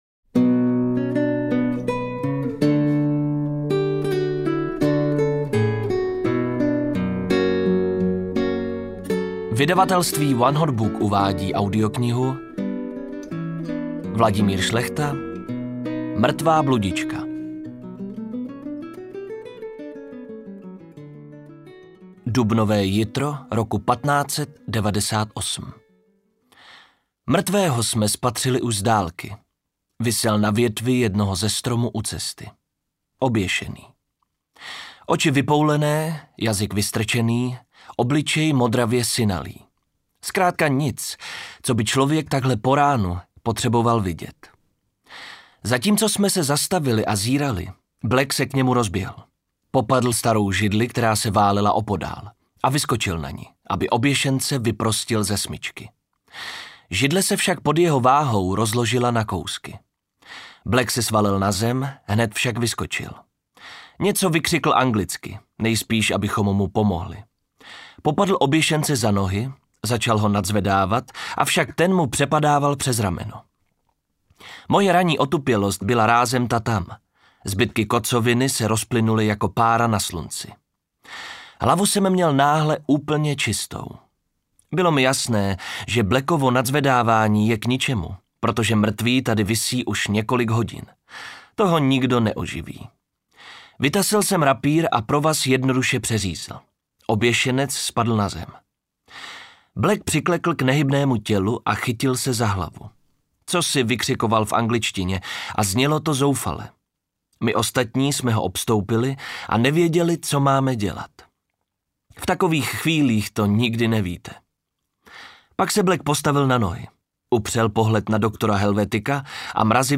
Audiobook
Read: Marek Lambora